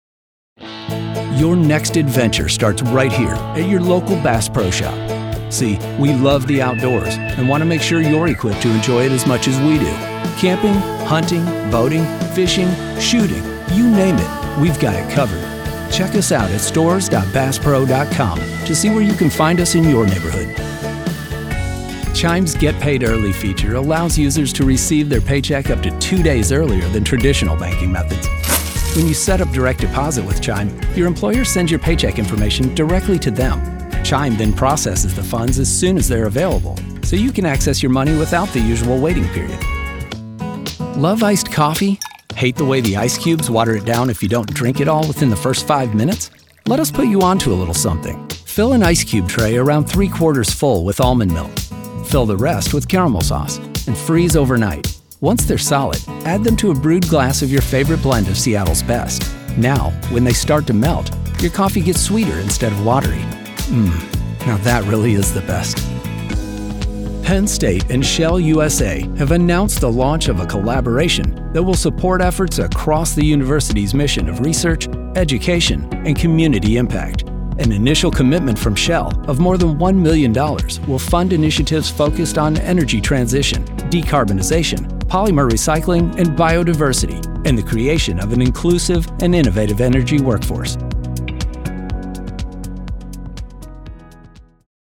American Male Voice Talent - Friendly, Relatable, Confident, Authentic, Real
Middle Aged